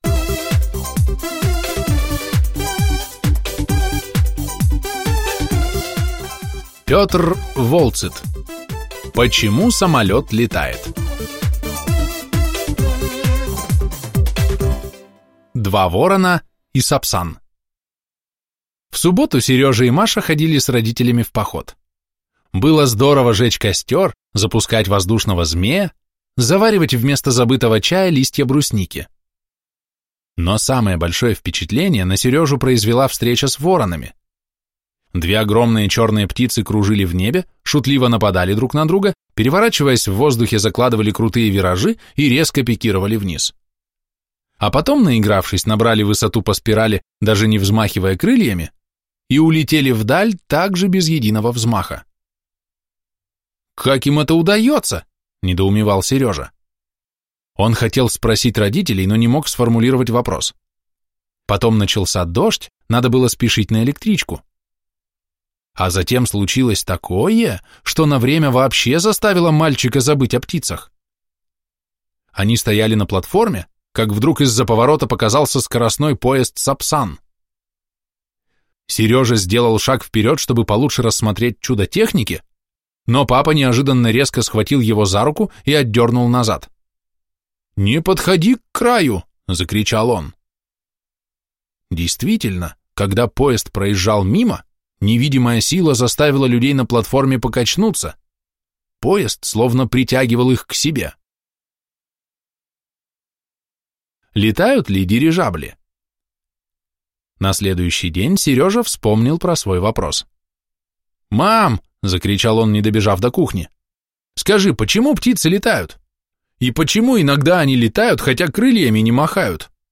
Аудиокнига Почему самолет летает?